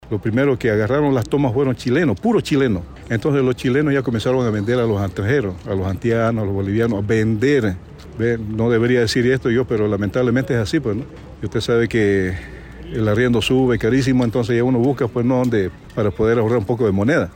Con respecto al origen de esta toma, uno de los pobladores de los lotes instalados señaló que la mayoría de las personas llegaron en el año 2020, pero que los primeros se trasladaron en el 2017, mayoritariamente chilenos, y posteriormente personas extranjeras arrendaban las viviendas ya construidas.